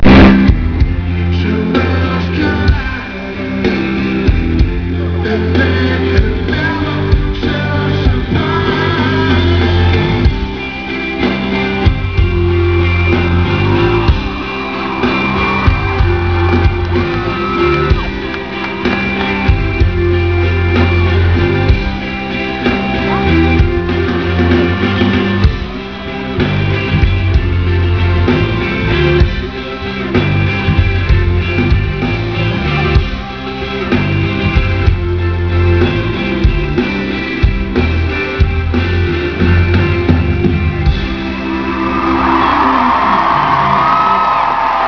for his encore